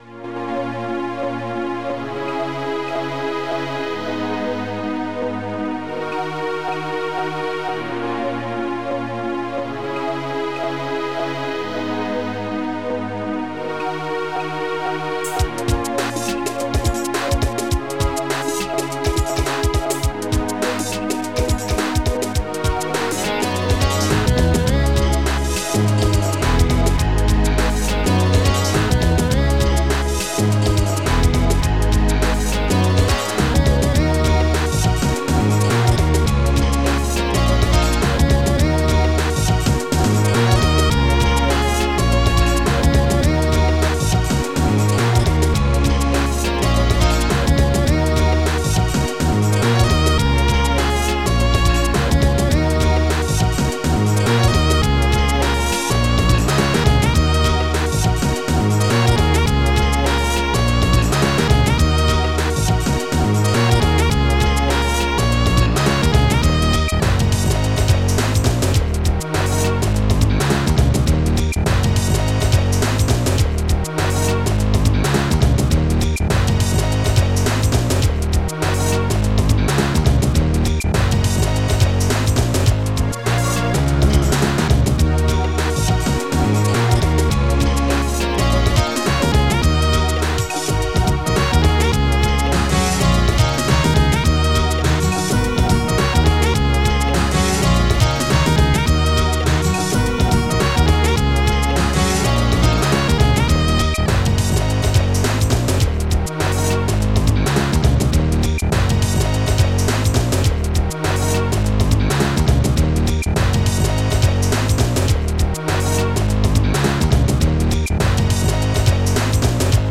Megatracker Module  |  1997-06-24  |  222KB  |  2 channels  |  44,100 sample rate  |  2 minutes, 14 seconds